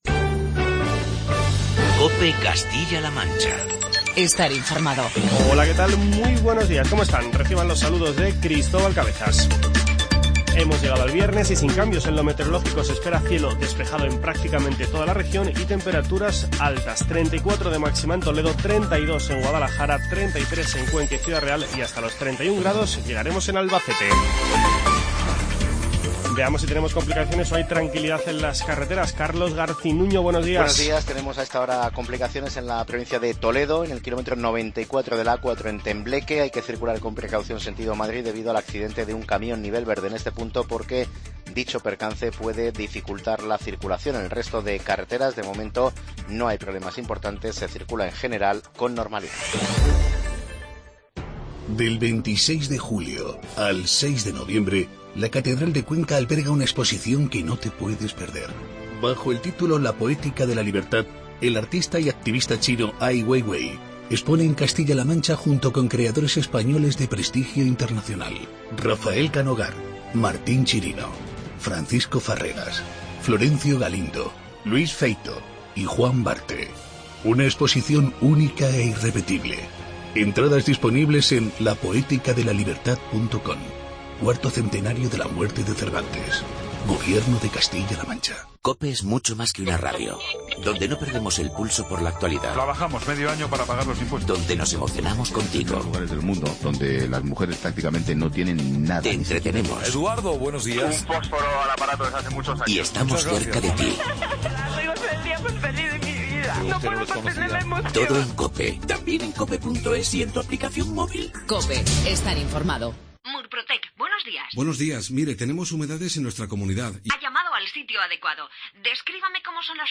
Informativo regional
Comenzamos este informativo con las palabras del vicepresidente de la Junta de Comunidades, José Luis Martínez Guijarro.